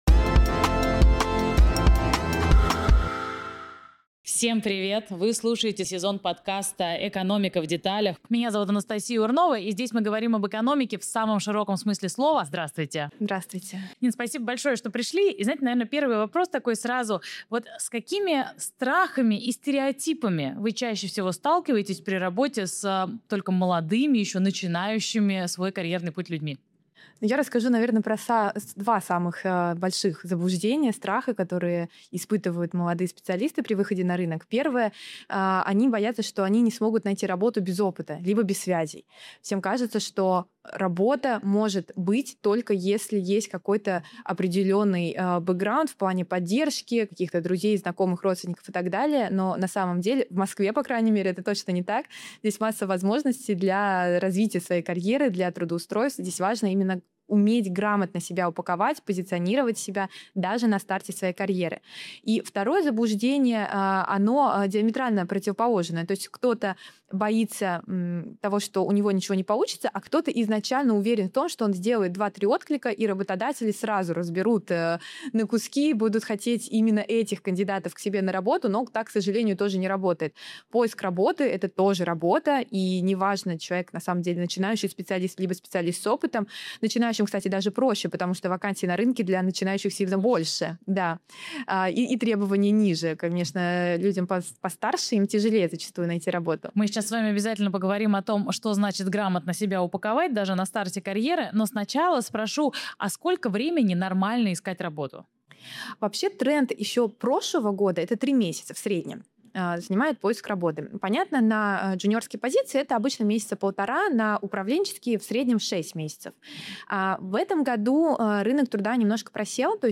В этом выпуске обсуждаем главные страхи и ошибки молодых специалистов. Эксперт рассказывает, почему не стоит соглашаться на первое же рабочее предложение, как грамотно упаковать резюме, не имея опыта, и как студенту начать строить карьерный трек, используя стажировки и волонтерские проекты. Подкаст «Экономика в деталях» — цикл бесед об устройстве городской экономики и о грамотном подходе к жизни и самореализации в мегаполисе.